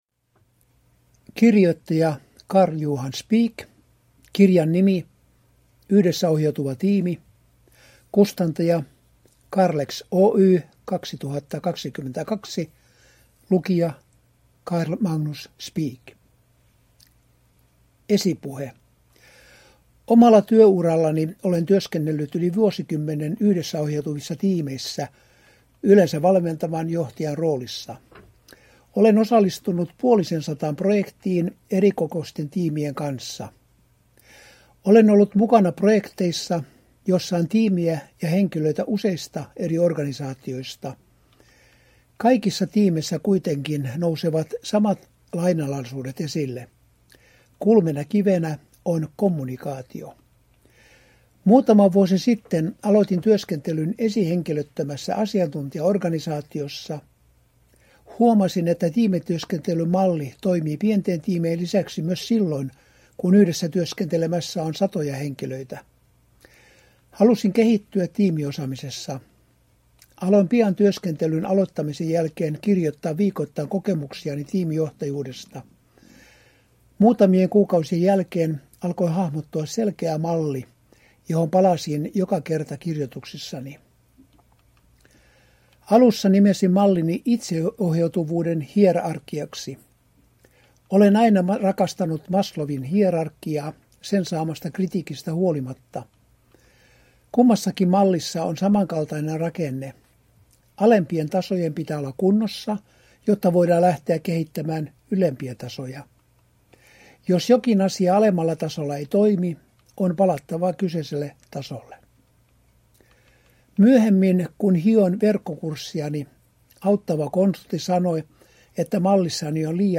Yhteisöohjautuvuus : Yhdessäohjautuva tiimi – Ljudbok